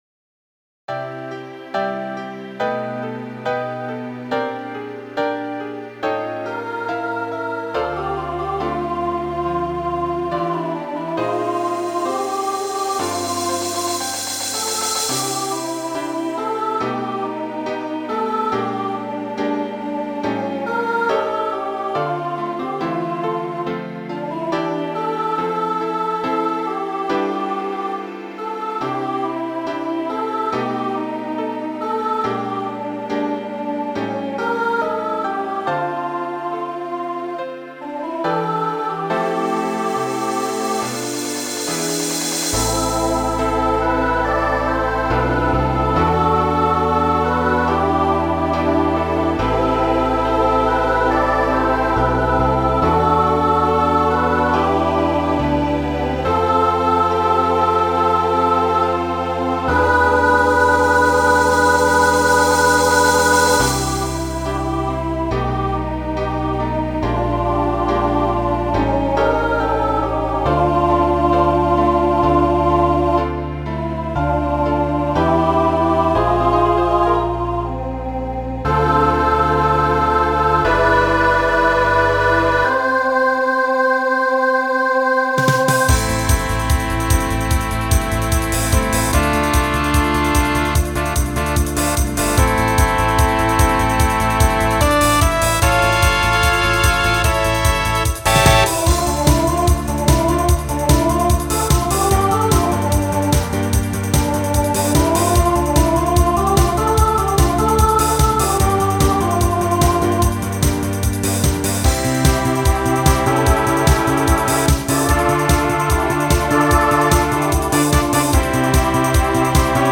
Voicing SSA Instrumental combo Genre Disco , Pop/Dance